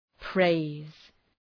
Προφορά
{preız}